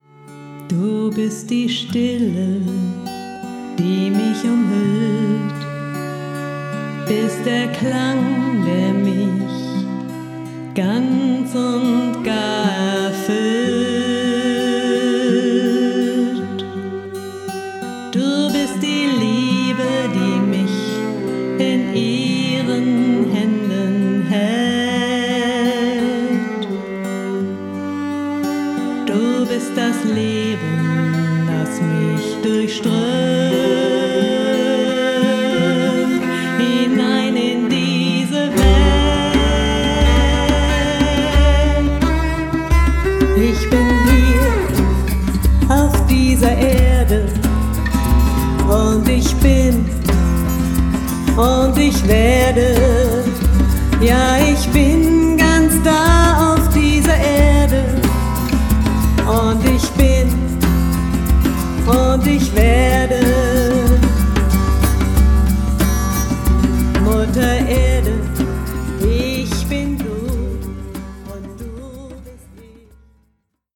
Gitarre, Stimme
Cello
Saxophon, Querflöte, Percussion